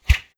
Close Combat Swing Sound 58.wav